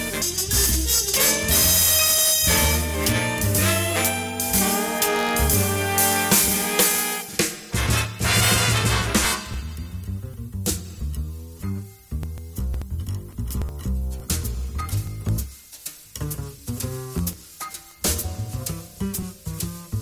Moving upwards, I spotted a larger waveform and a conspicuous spike on the signal scope.
Wasn’t expecting music this clear so low on the spectrum, as traditional FM broadcasting does not go as low as 24.539 FM. This could be something like broadcasting muzak for a elevator, building, or shop.
lowSpectrumJazz.mp3